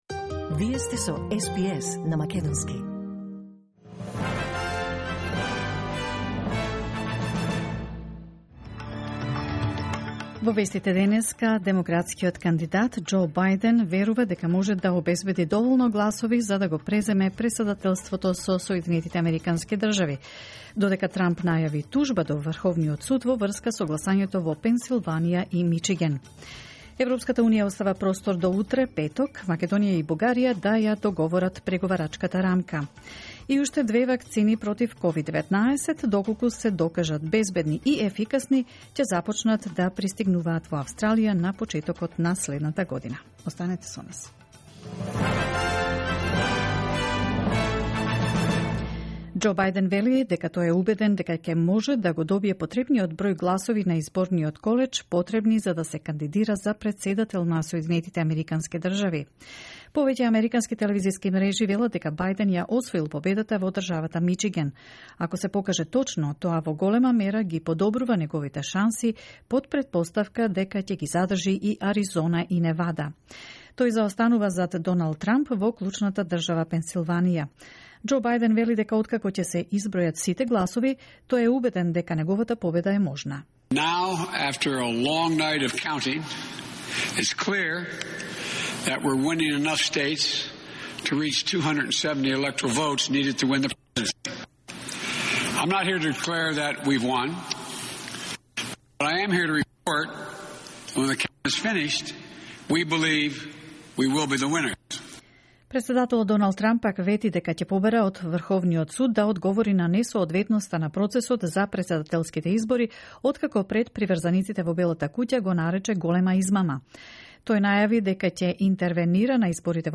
SBS News in Macedonian, 5 November 2020